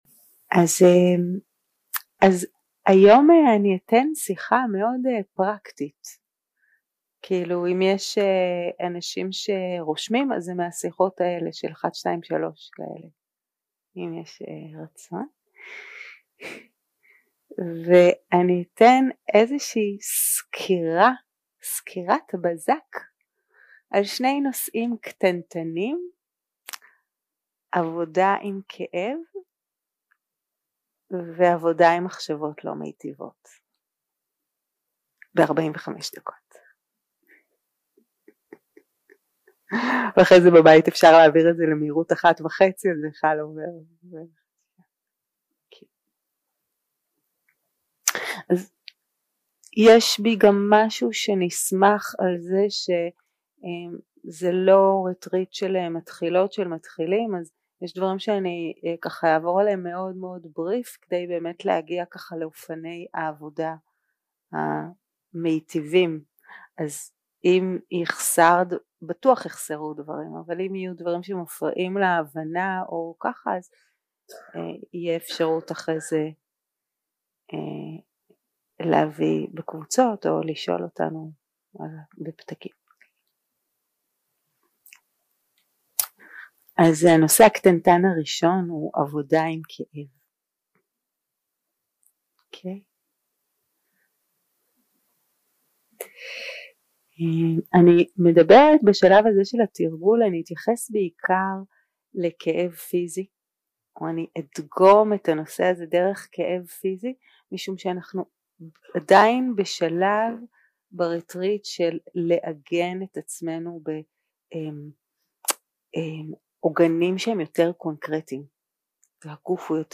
יום 3 - הקלטה 6 - אחהצ - שיחת דהרמה - עבודה עם כאב ומחשבות לא מיטיבות 0:00 0:00 סוג ההקלטה: Dharma type: Dharma Talks שפת ההקלטה: Dharma talk language: Hebrew